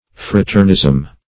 Search Result for " fraternism" : The Collaborative International Dictionary of English v.0.48: Fraternation \Fra`ter*na"tion\, Fraternism \Fra"ter*nism\, n. Fraternization.
fraternism.mp3